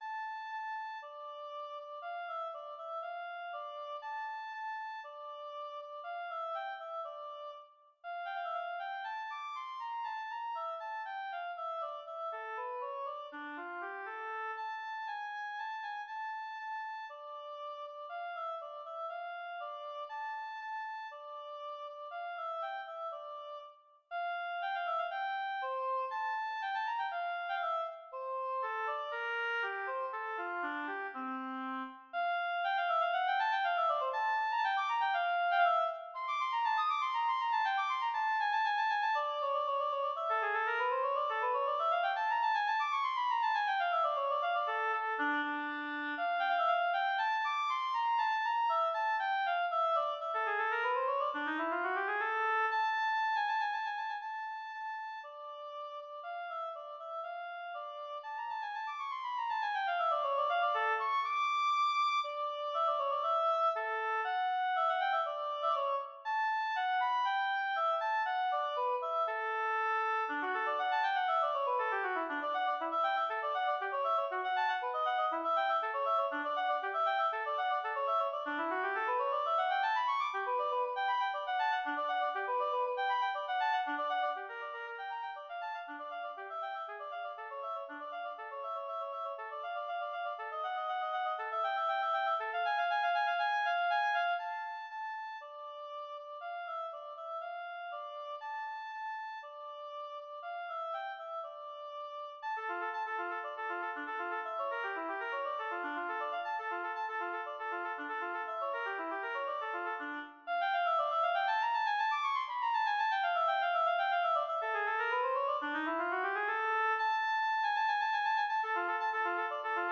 Advanced oboe solo
Instrumentation: Solo oboe